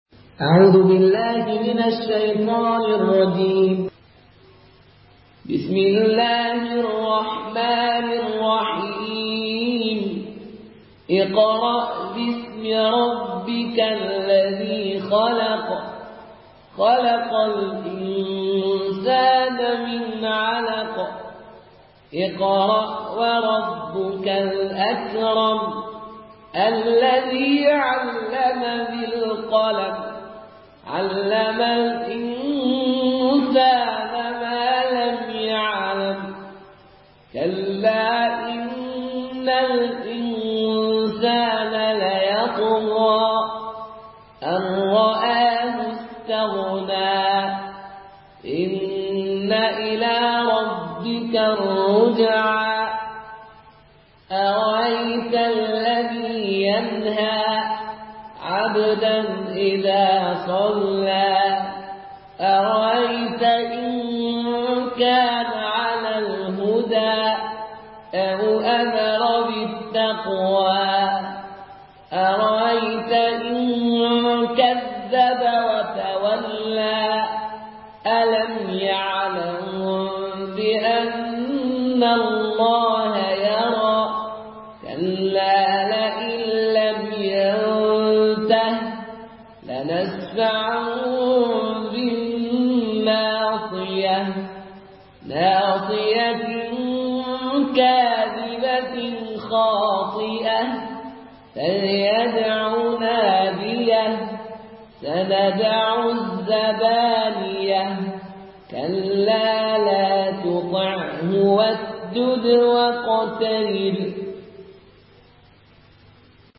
Murattal